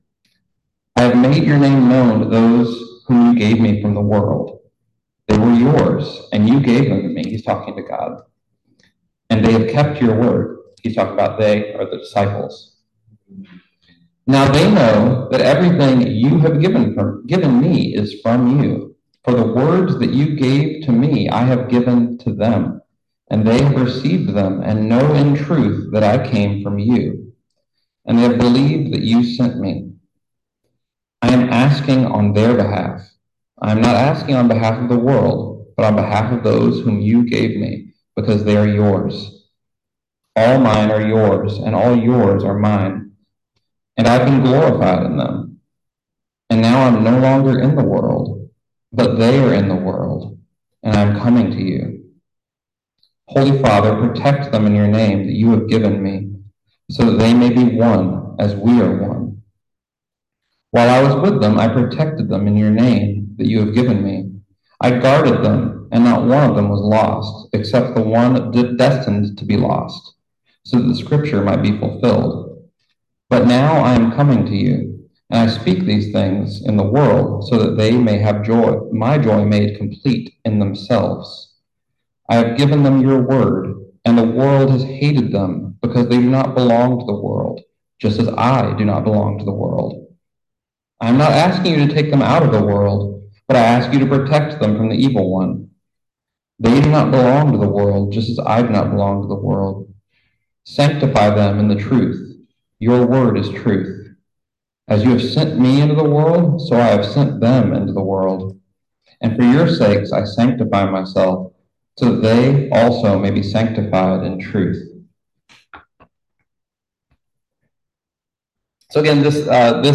Listen to the most recent message from Sunday worship at Berkeley Friends Church, “Your Word Is Truth.”